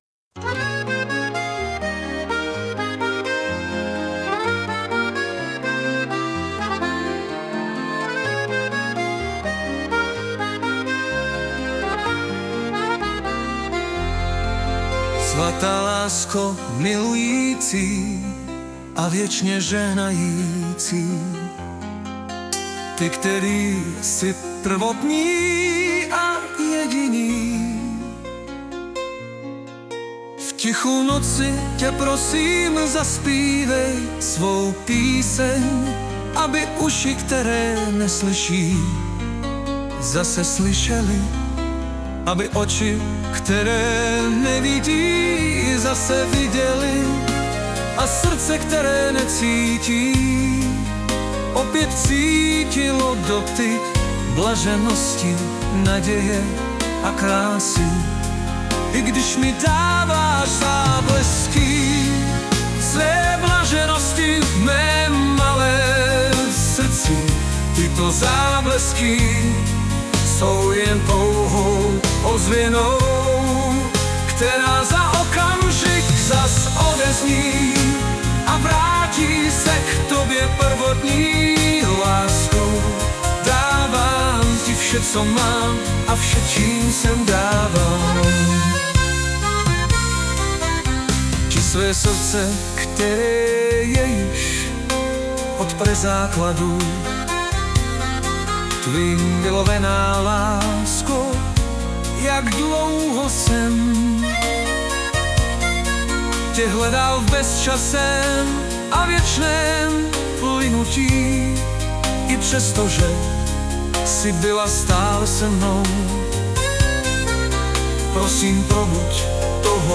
2012 & Hudba a Zpěv: AI